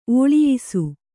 ♪ ōḷiyisu